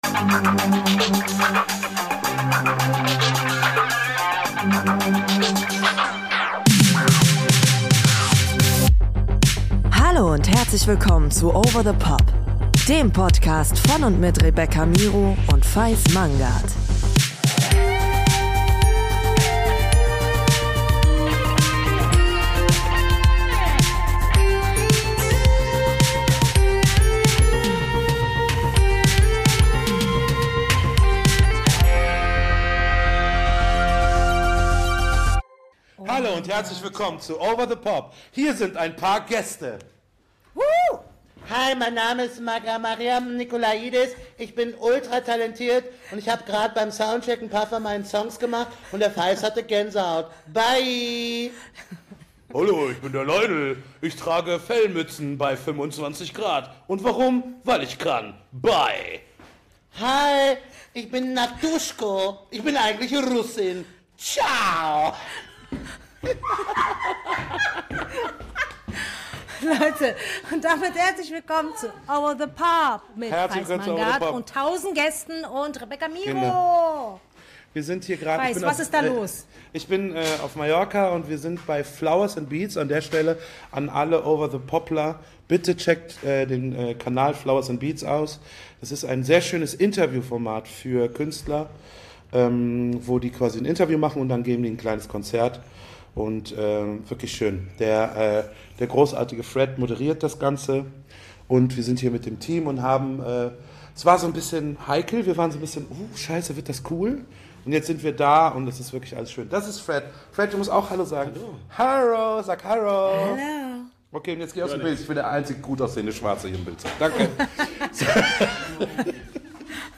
Schaut bis fast zum Ende, was für einen Song plötzlich ALLE singen!!!...